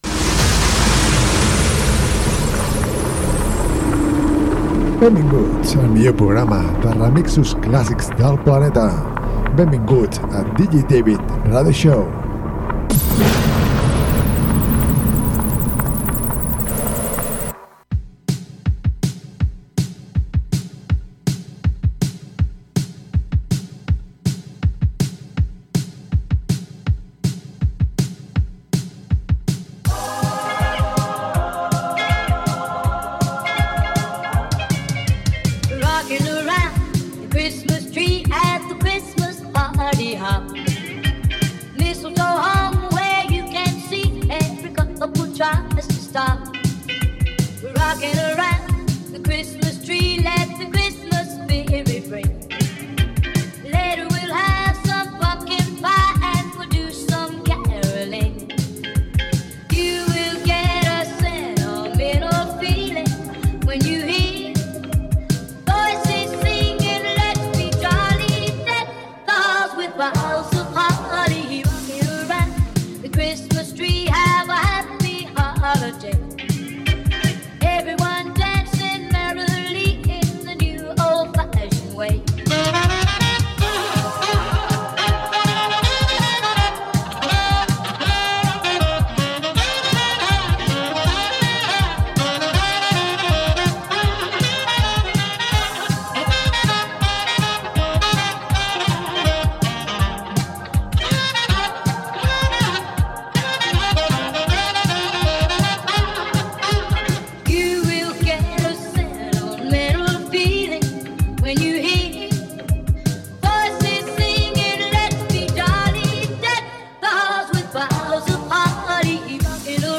remixos classics